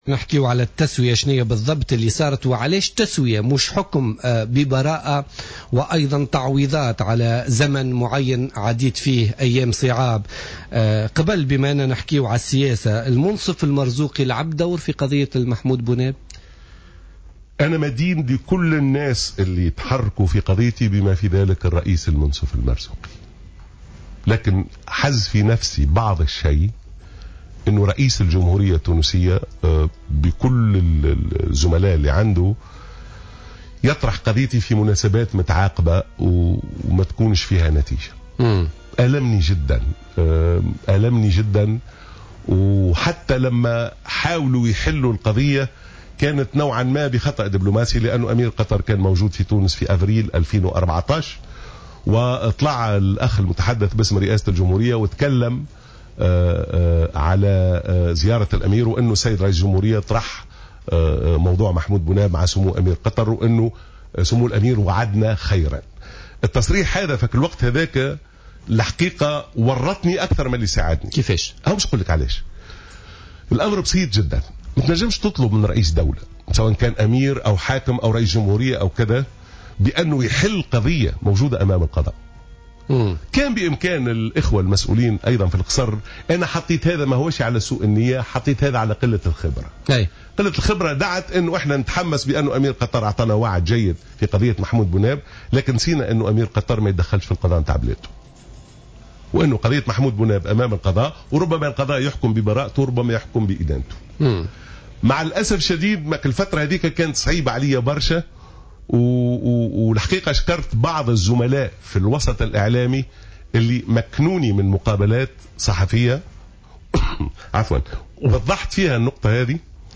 ضيف برنامج "بوليتيكا"